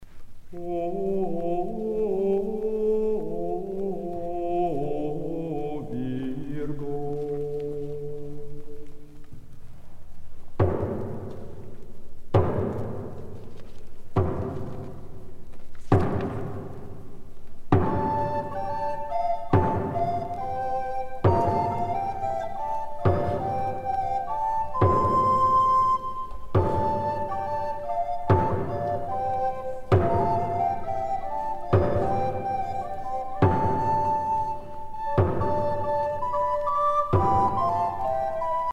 danse sacrée
Pièce musicale éditée